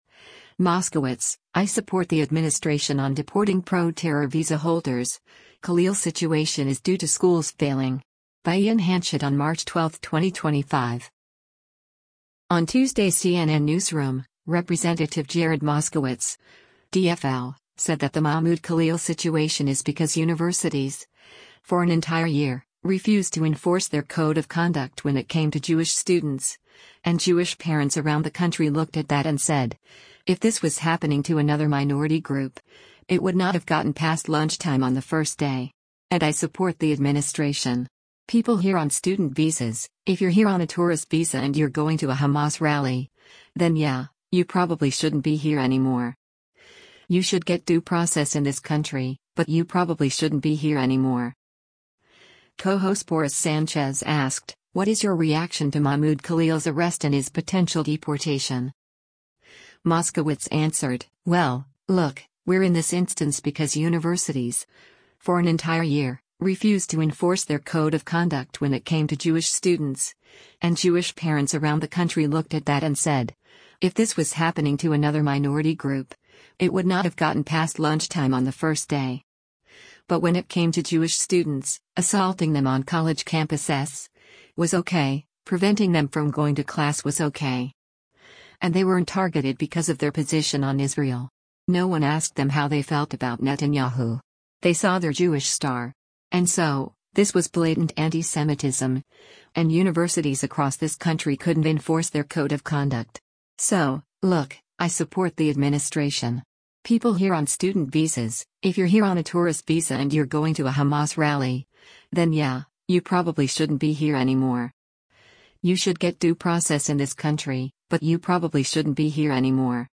Co-host Boris Sanchez asked, “What is your reaction to Mahmoud Khalil’s arrest and his potential deportation?”